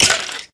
1.21.5 / assets / minecraft / sounds / mob / stray / hurt3.ogg
hurt3.ogg